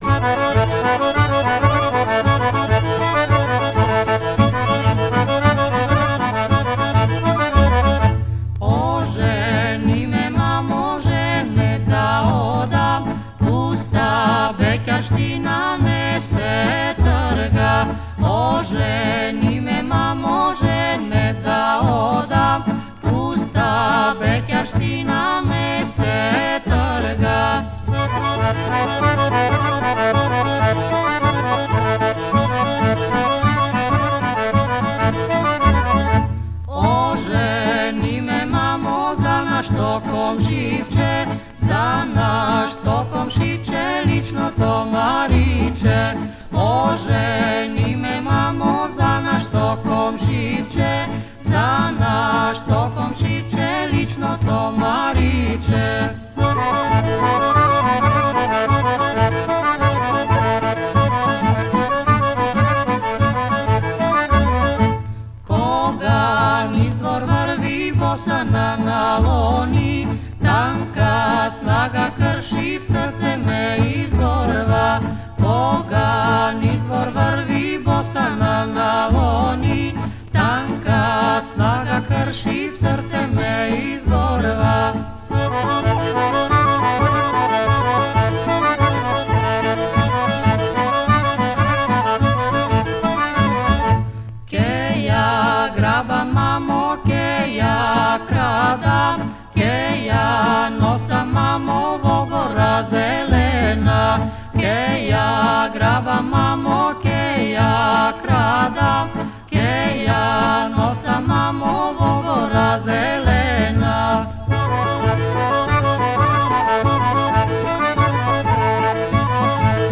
Genre: National Folk